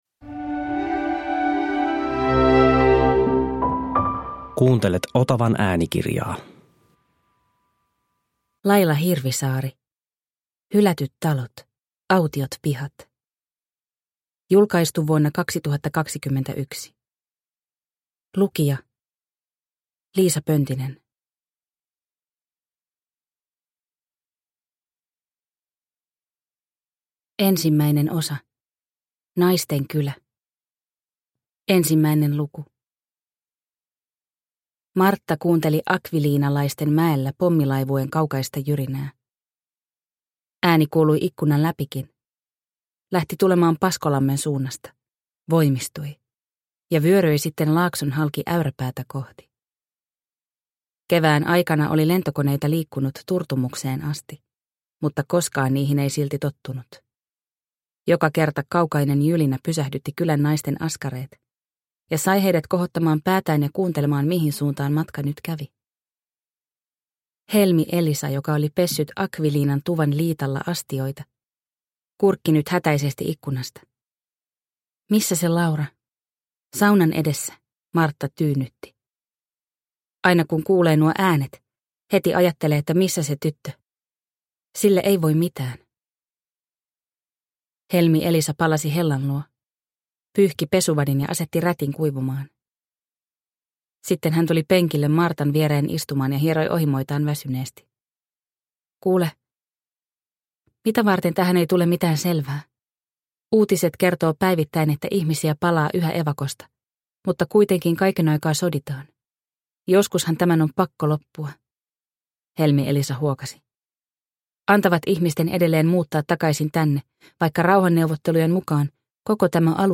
Hylätyt talot autiot pihat – Ljudbok – Laddas ner